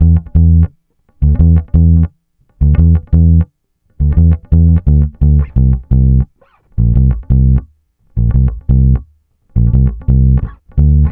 Track 02 - Bass 02.wav